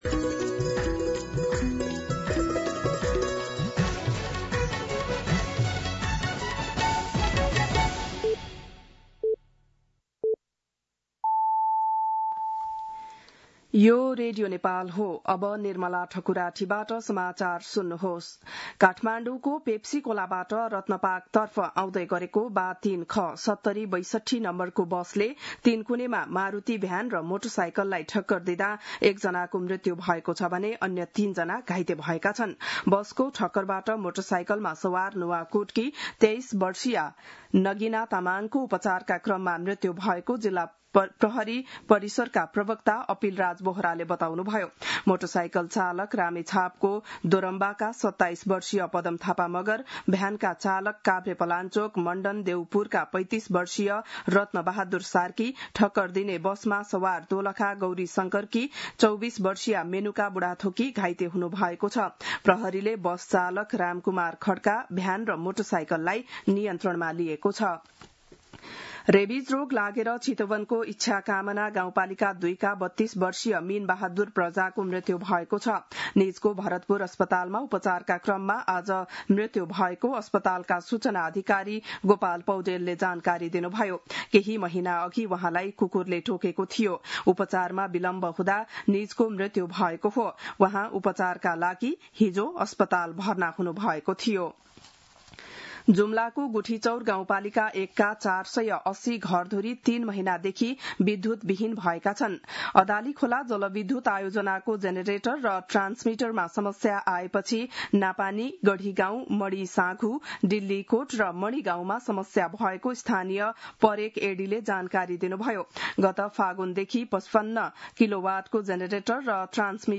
बिहान ११ बजेको नेपाली समाचार : २५ वैशाख , २०८२
11-am-Nepali-News-1.mp3